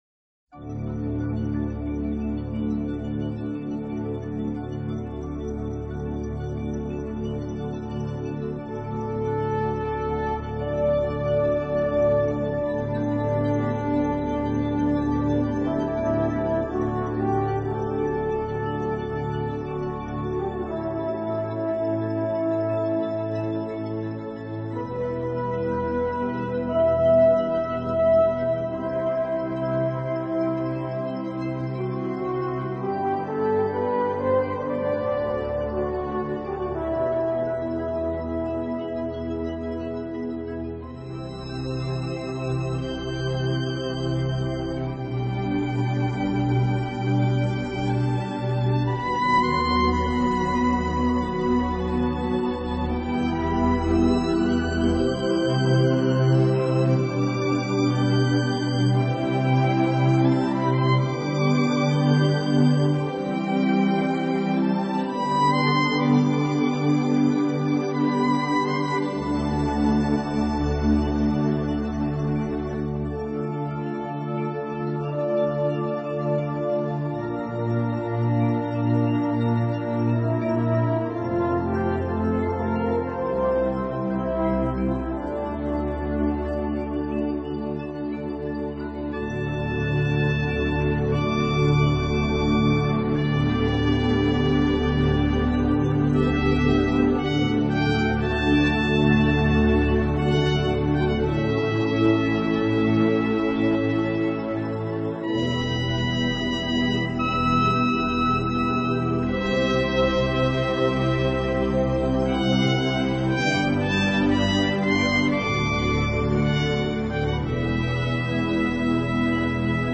这就是古典元素（Oboe, Flauto, Corno, Archi, Pianoforte）和现
代元素（Batteria, Chitarra Basso, Synth）如何在作品当中相互融合的过程。